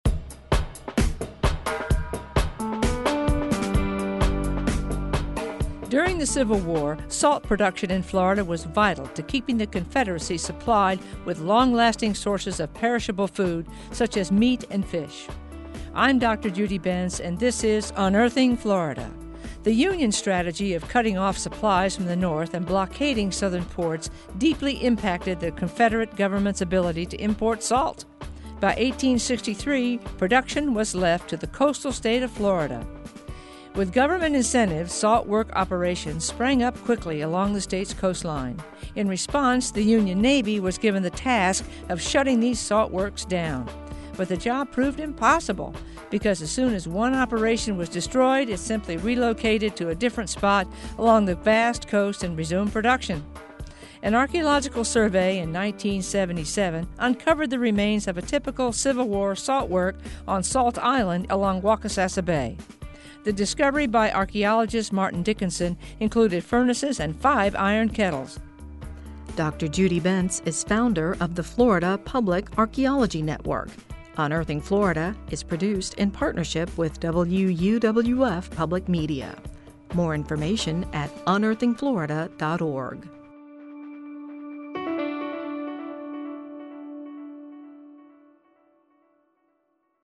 narrated